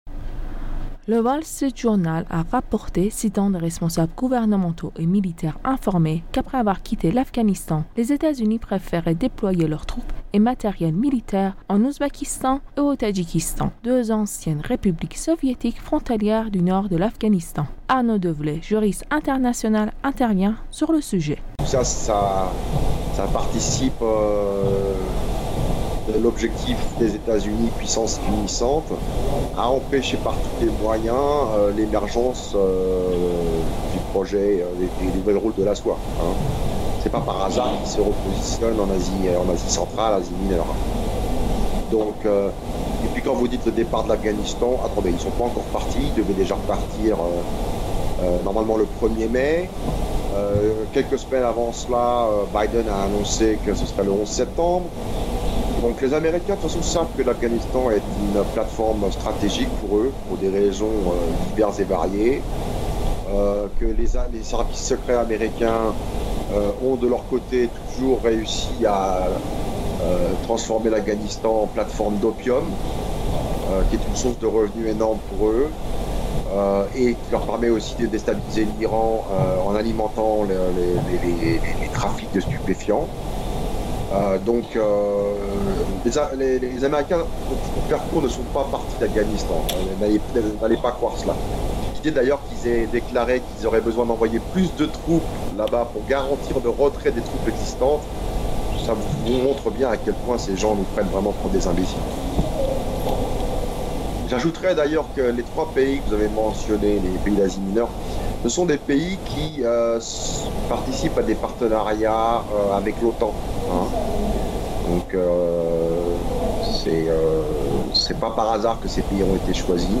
juriste international, intervient sur le sujet.